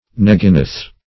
neginoth \neg"i*noth\, n. pl. [Heb. n[e^]g[imac]n[=o]th.]